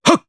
Riheet-Vox_Attack2_jp.wav